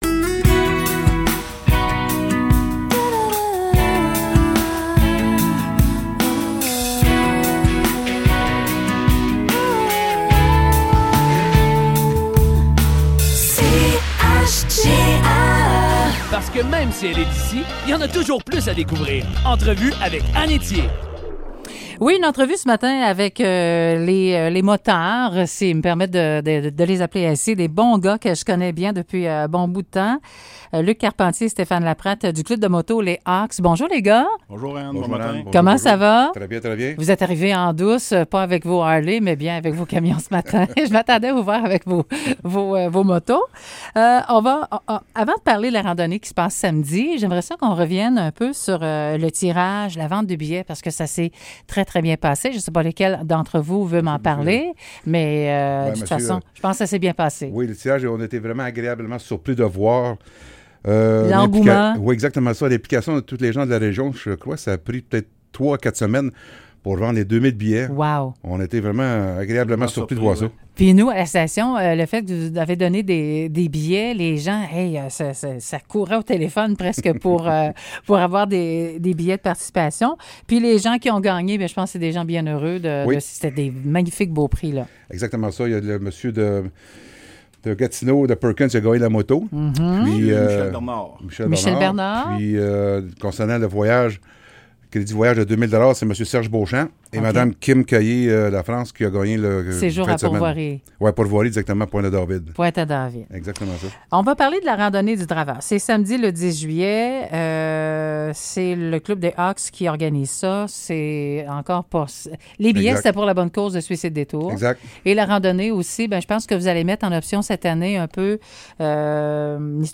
Entrevues